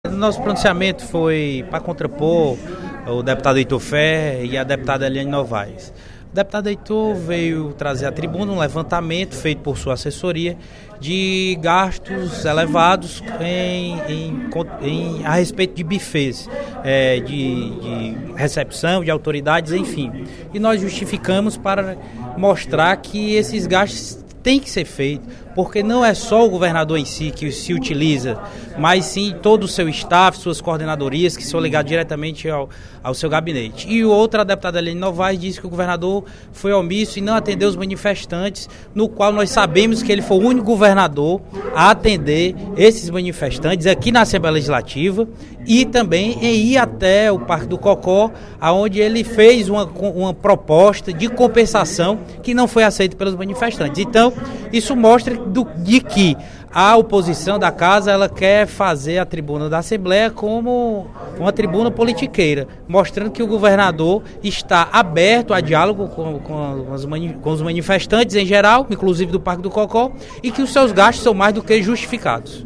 Durante o primeiro expediente da sessão plenária desta terça-feira (13/08), o vice-líder do Governo na Casa, deputado Júlio César Filho (PTN), respondeu as críticas feitas pelos deputados Heitor Férrer (PDT) e Elaine Novais (PSB) ao governador Cid Gomes.